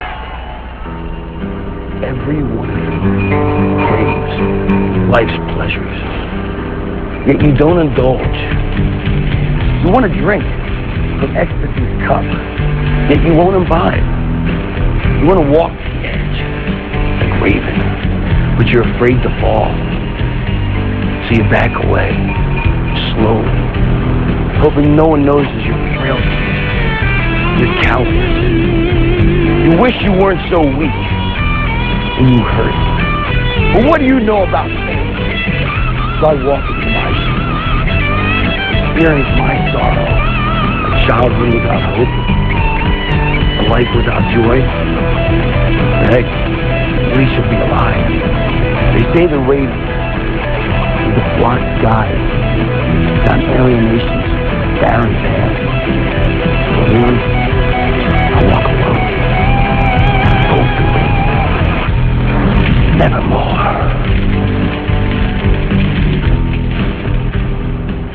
- This speech comes from Nitro - [7.3.98]. This speech comes from a promo video for Raven, where he says that he isn't the Flock's guide, but rather walks alone.